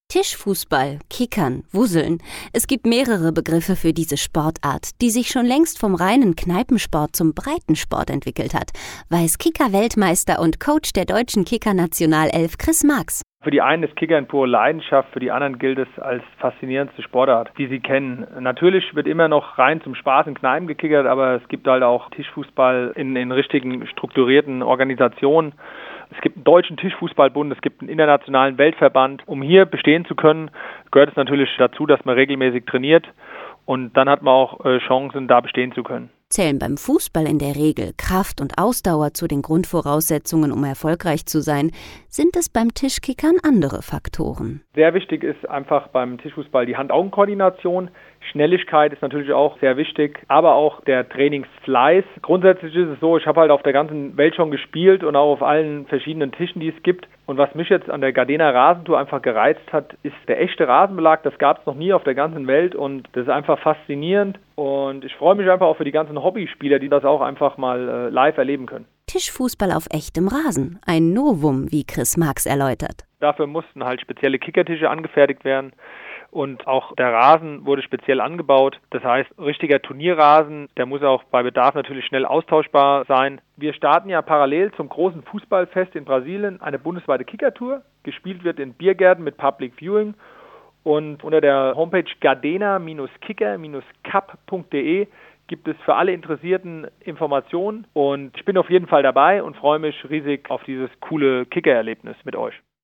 Beitrag